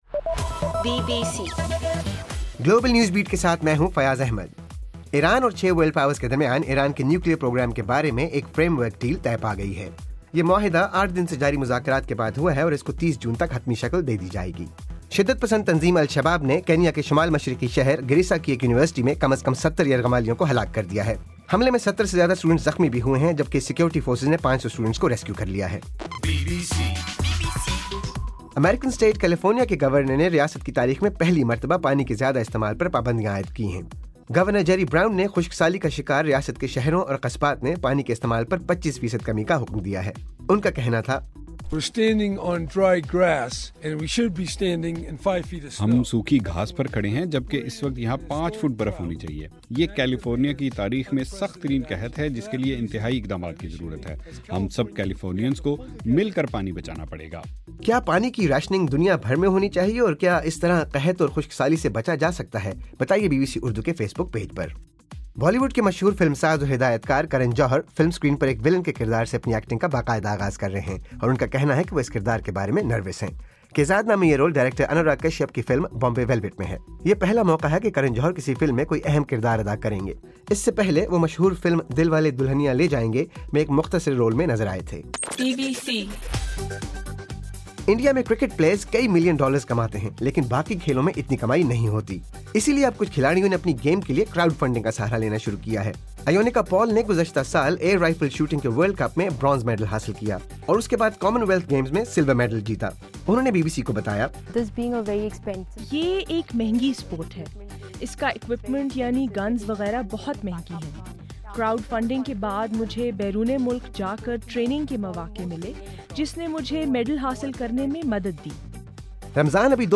اپریل 2: رات 12 بجے کا گلوبل نیوز بیٹ بُلیٹن